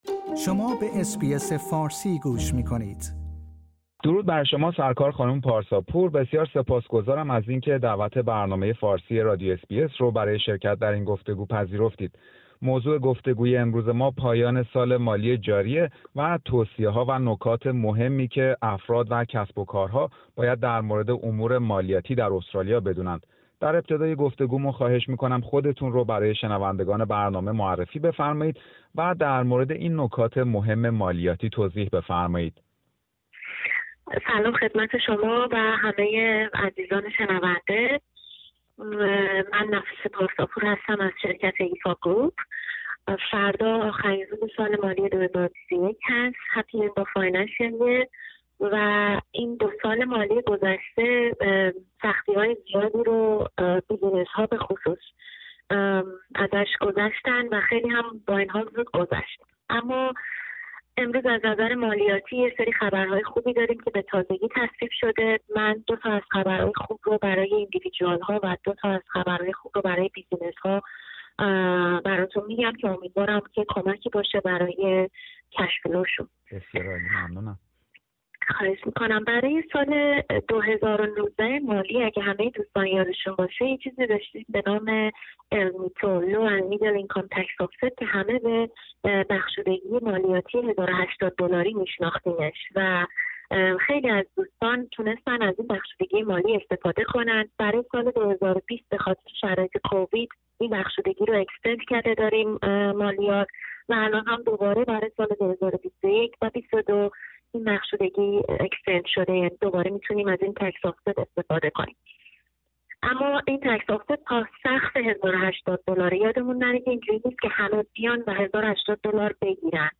گفتگویی در مورد پایان سال مالی جاری و نگاهی به برخی نکات مهم مالیاتی